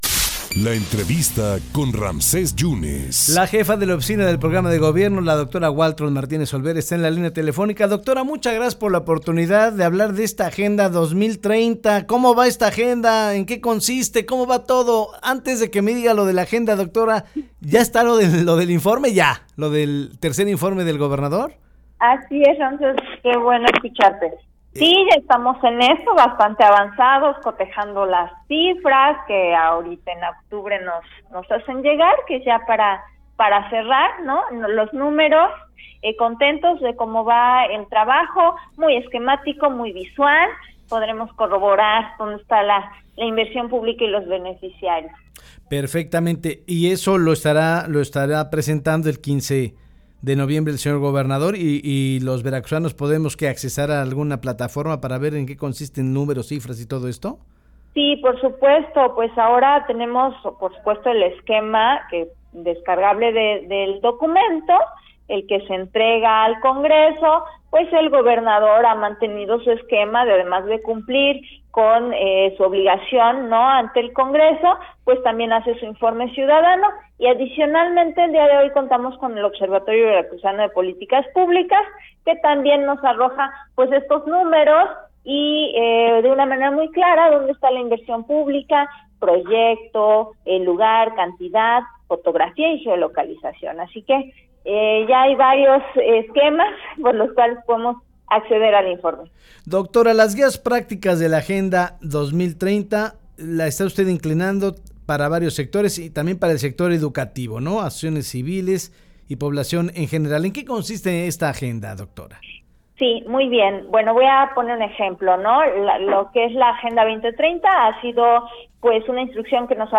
Waltraud Martínez Olvera informó en entrevista para En Contacto de Avanoticias sobre las guías prácticas que se pueden descargar en línea rumbo a la implementación de la Agenda 2030 en Veracruz tal y como se ha instruido aplicarla en el gobierno del estado y que, a decir de la funcionaria, corresponde a todos los veracruzanos para llegar a un mejor contexto social.